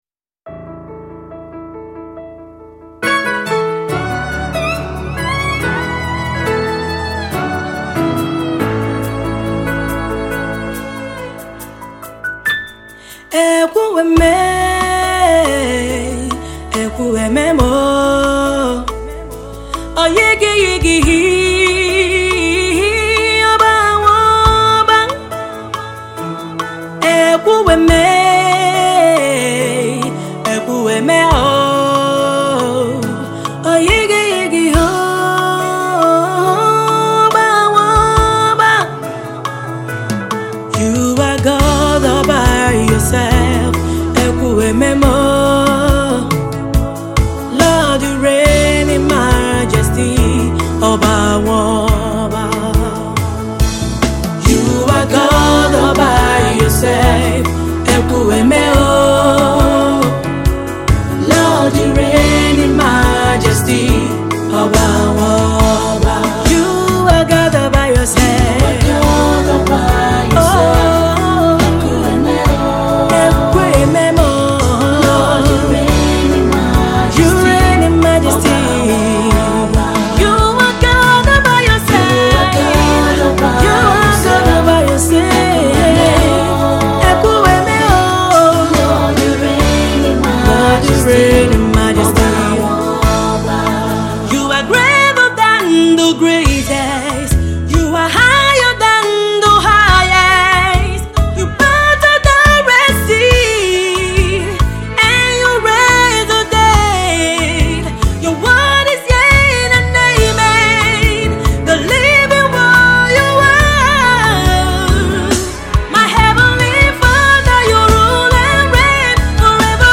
worship song